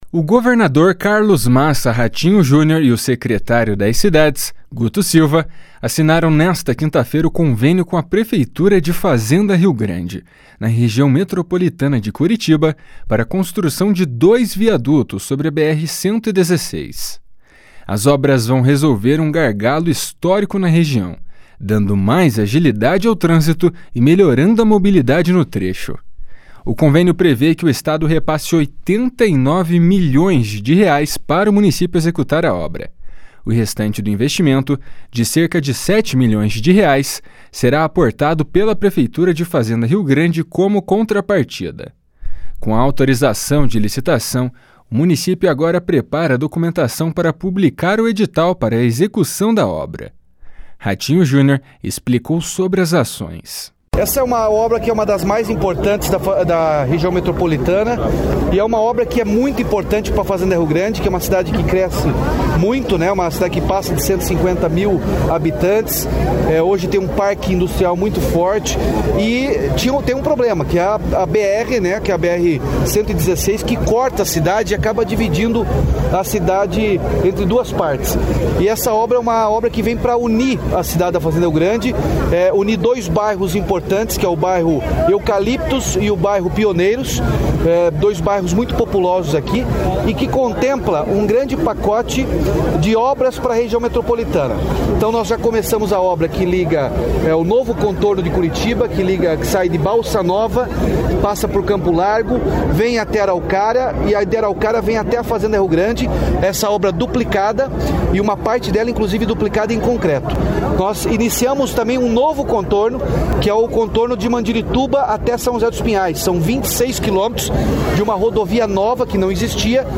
Ratinho Junior explicou sobre as ações.
O secretário de Cidades, Guto Silva, explica sobre o funcionamento da obra.
O prefeito de Fazenda Rio Grande, Marco Marcondes, destaca a importância da obra.